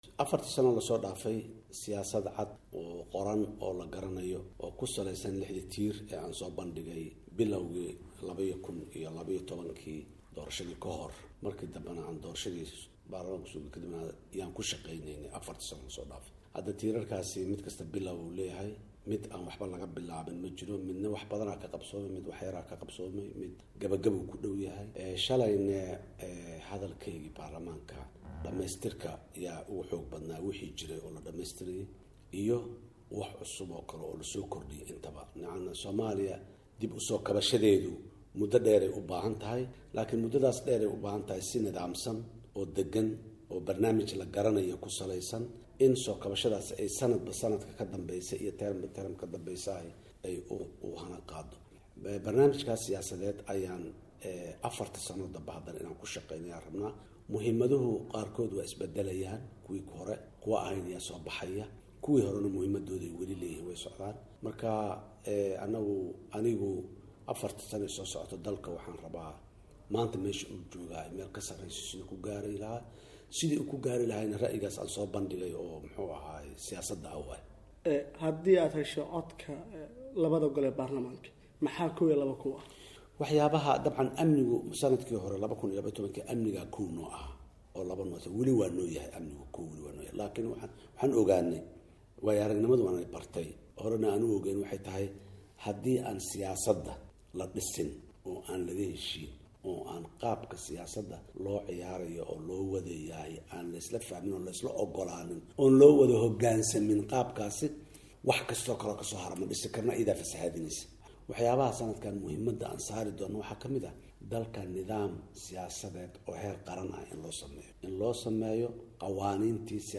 Wareysi: Madaxweyne Xasan Sheekh
Madaxweynaha Soomaaiya Xasan Sheekh Maxmauud oo ah musharax mar kale u taagan xilka ayaa waraysi uu siiyay VOA-da waxa uu kaga hadlay waxayaabihii u suura gelin waayey in uu gaaro ballanqaadkiisii 2012-ka.